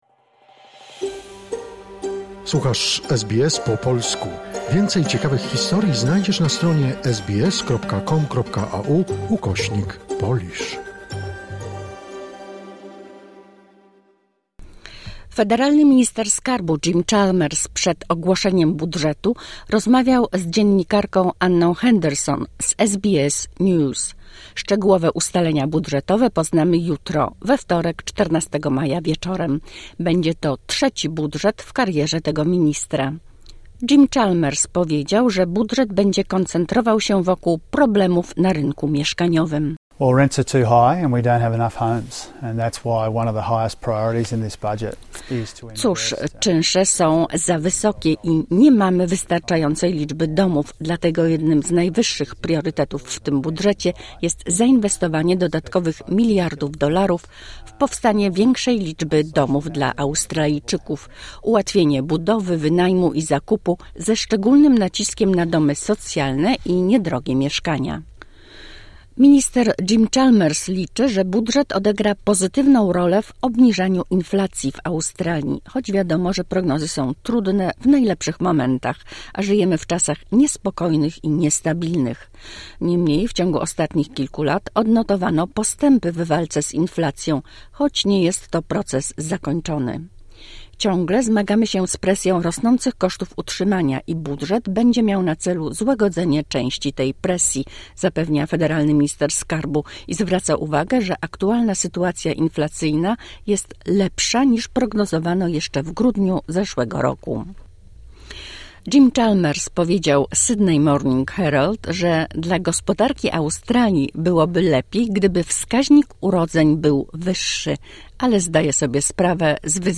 Minister Jim Chalmers rozmawiał z SBS News